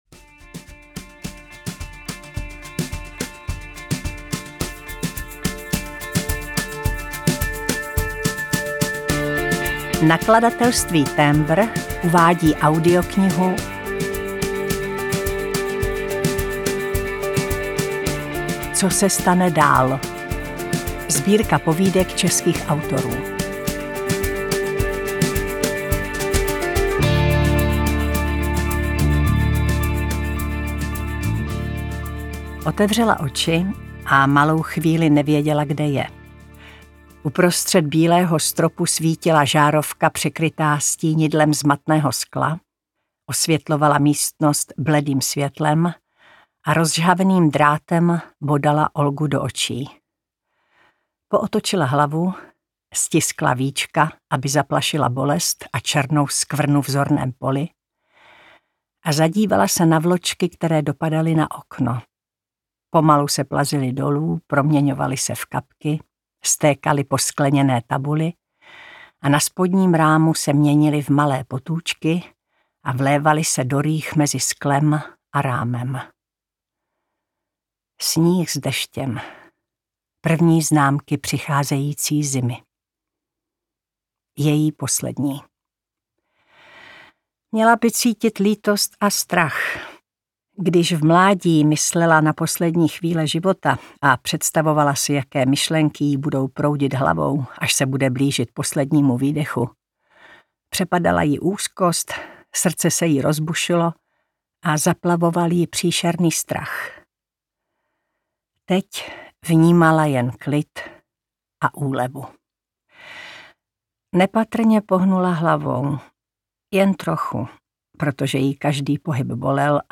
Co se stane dál - Markéta Pilátová, Kateřina Tučková, Petra Dvořáková, Alena Mornštajnová, Aňa Geislerová, Viktorie Hanišová, Michaela Klevisová, Dora Čechova, Irena Hejdová, Anna Bolavá - Audiokniha